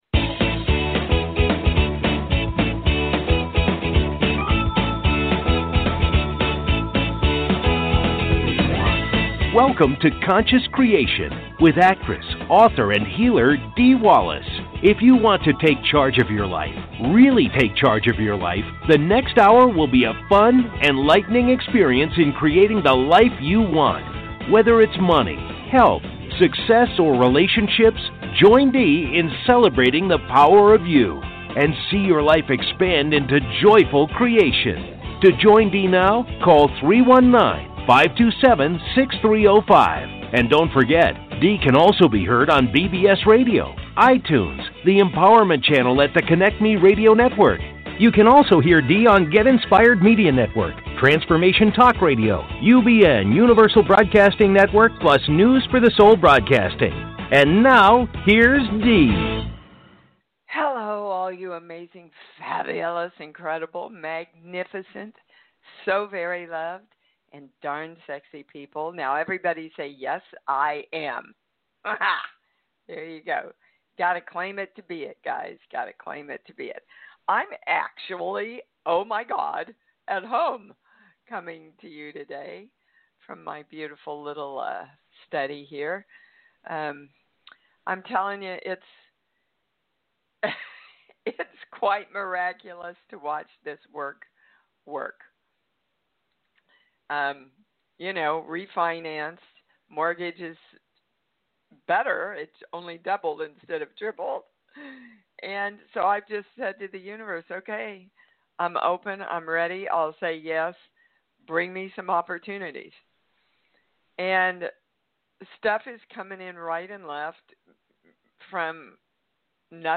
Talk Show Episode, Audio Podcast, Conscious Creation and with Dee Wallace on , show guests